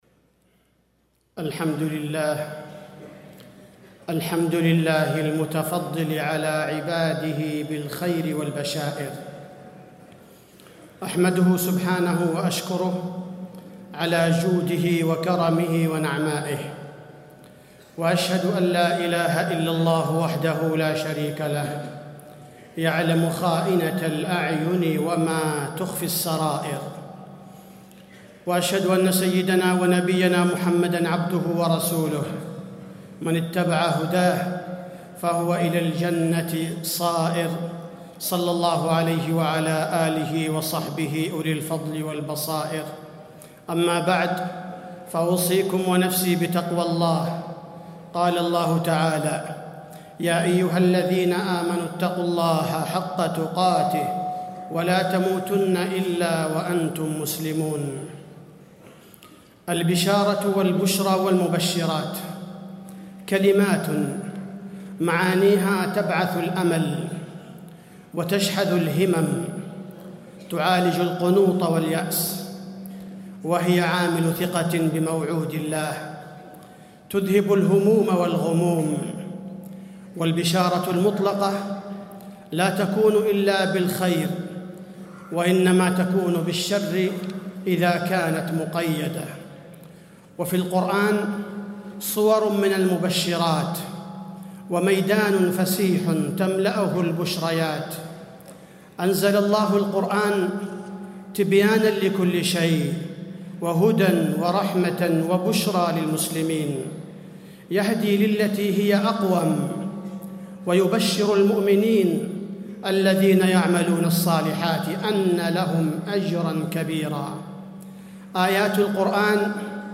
تاريخ النشر ٥ محرم ١٤٣٥ هـ المكان: المسجد النبوي الشيخ: فضيلة الشيخ عبدالباري الثبيتي فضيلة الشيخ عبدالباري الثبيتي البشارة في القرآن والسنة